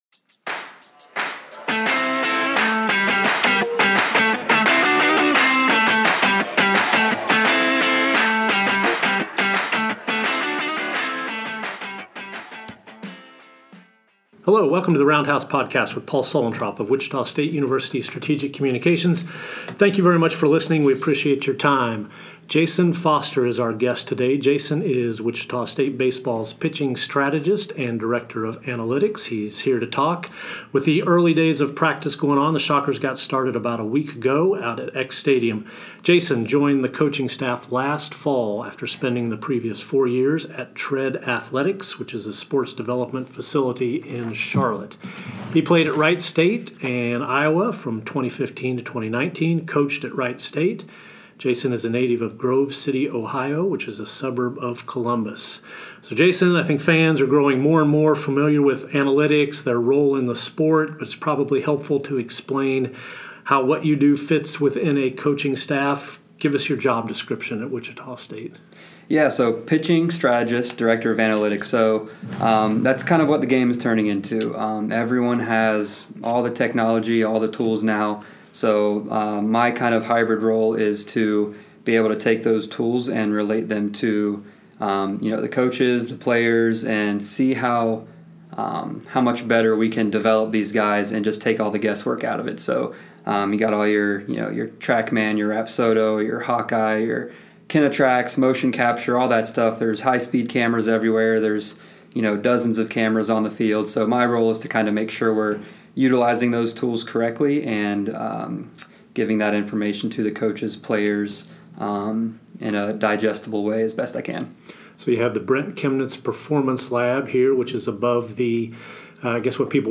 Roundhouse podcast